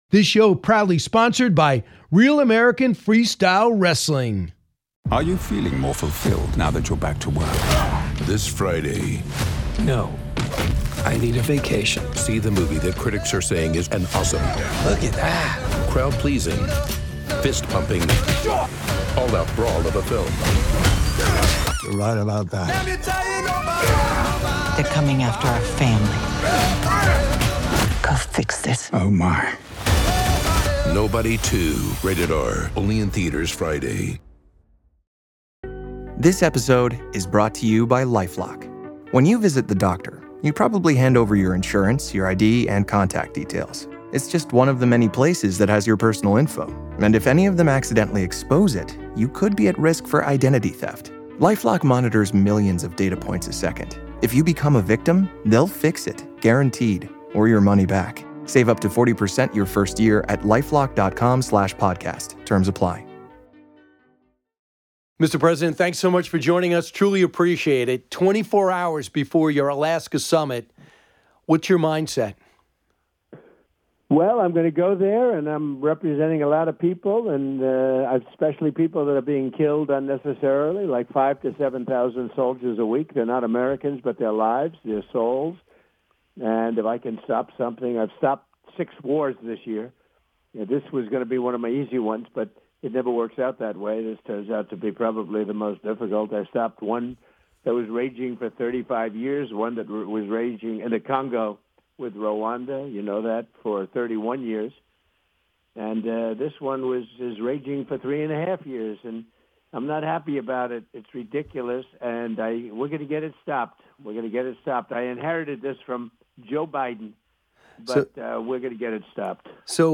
President Donald Trump joined Brian Kilmeade on The Brian Kilmeade Show for an extended interview covering many issues.